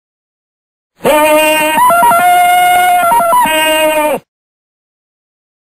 دانلود آهنگ تارزان 2 از افکت صوتی انسان و موجودات زنده
جلوه های صوتی
دانلود صدای تارزان 2 از ساعد نیوز با لینک مستقیم و کیفیت بالا